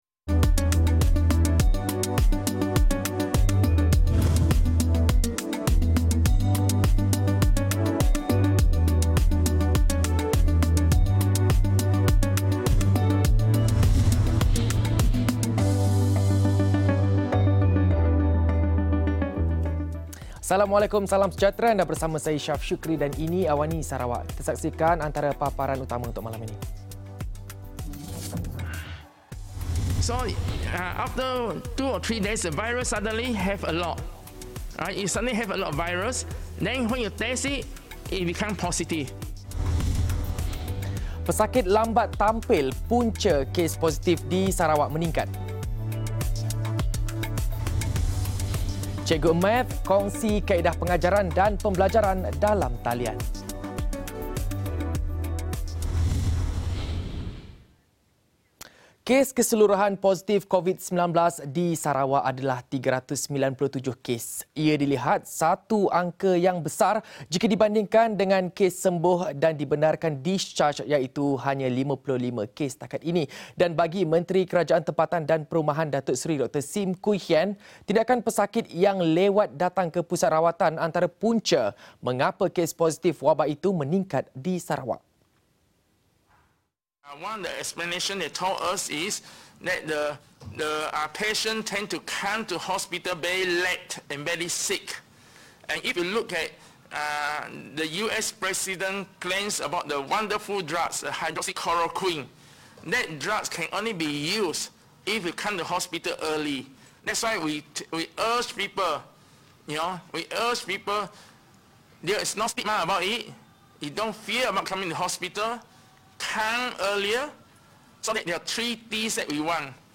Laporan berita ringkas dan padat